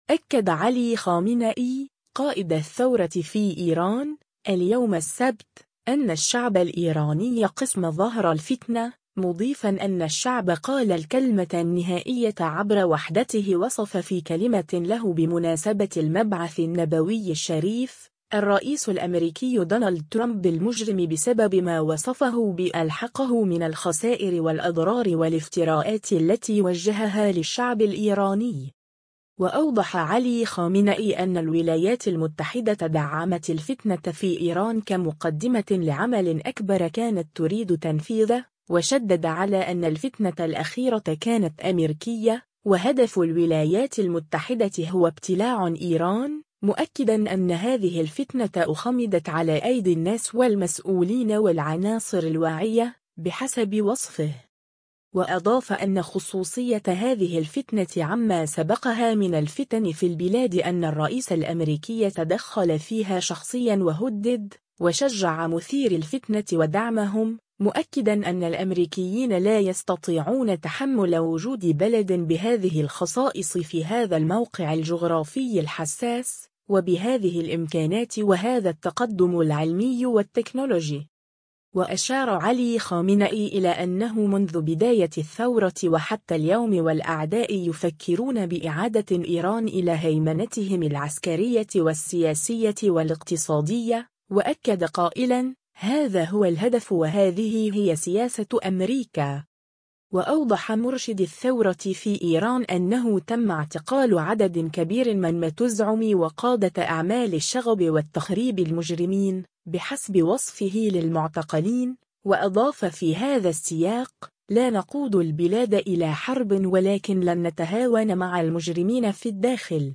أكد علي خامنئي ، قائد الثورة في إيران ، اليوم السبت، أنّ الشعب الإيراني قصم ظهر الفتنة، مضيفاً أن «الشعب قال الكلمة النهائية عبر وحدته» وصف في كلمة له بمناسبة المبعث النبوي الشريف، الرئيس الأمريكي دونالد ترامب بـ«المجرم» بسبب ما وصفه بـ «ألحقه من الخسائر والأضرار والافتراءات التي وجّهها للشعب الإيراني».